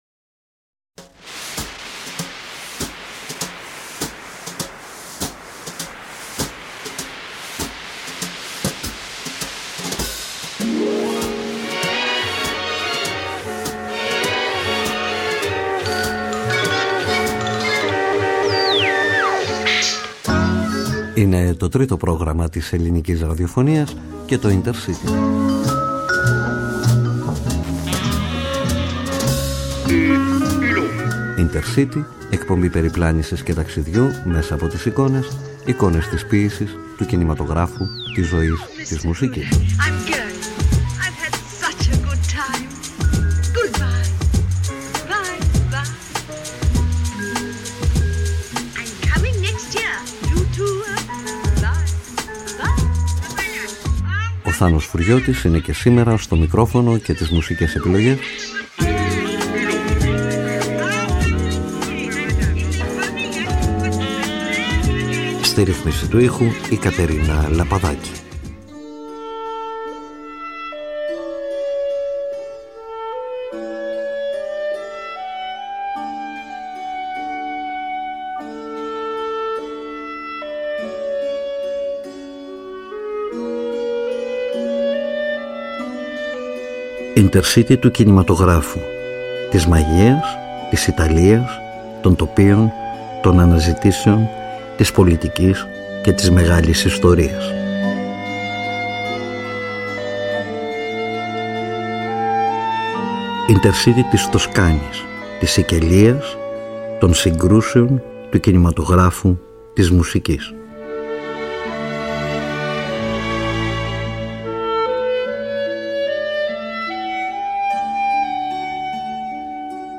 Kαι κάτω από τις λέξεις φιλία, αδελφοσύνη και πίστη (αλλά και με την μουσική των ταινιών τους (που θα την ακούσουμε από τις αυθεντικές της ηχογραφήσεις) μιλούν απλά για την αιωνιότητα… INTERCITY Εκπομπή περιπλάνησης και ταξιδιού μέσα από τις εικόνες: εικόνες της ποίησης, του κινηματογράφου, της ζωής, της μουσικής .